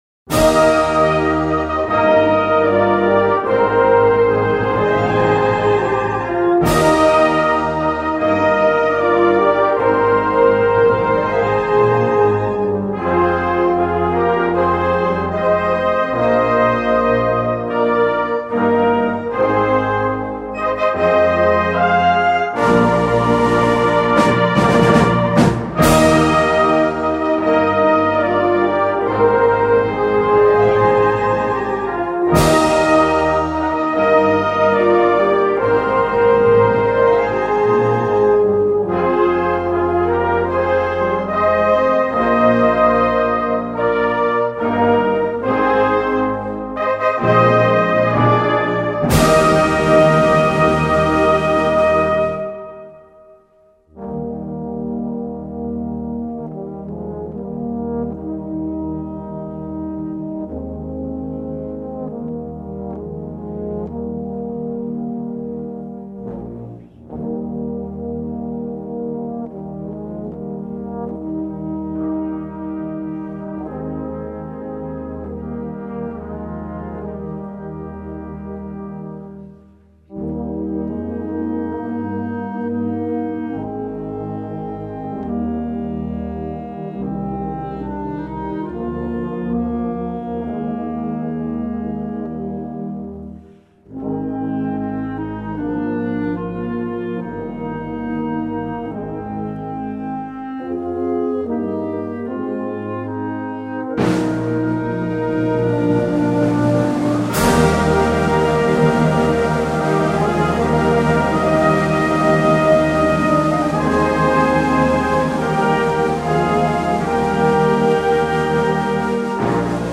Gattung: Ouvertüre für Blasorchester
Besetzung: Blasorchester